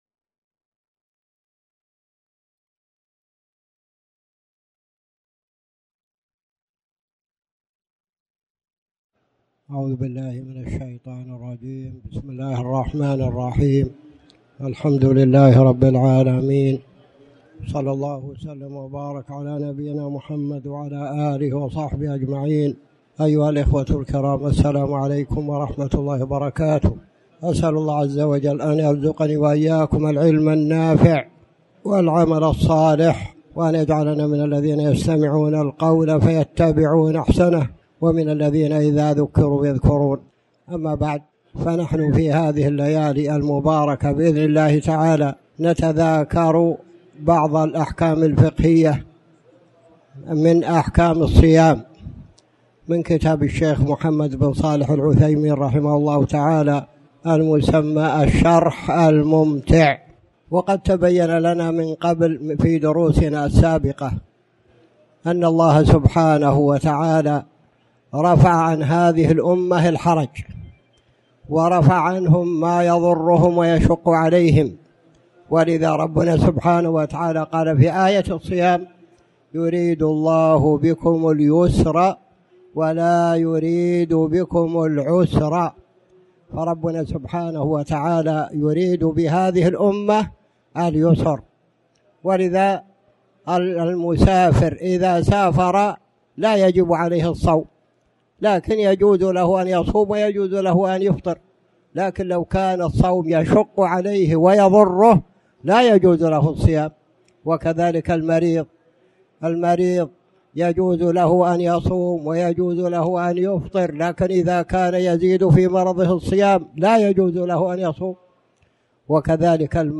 تاريخ النشر ٢٨ شعبان ١٤٣٩ هـ المكان: المسجد الحرام الشيخ